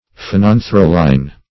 Search Result for " phenanthroline" : The Collaborative International Dictionary of English v.0.48: Phenanthroline \Phe*nan"thro*line\, n. [Phenanthrene + quinoline.]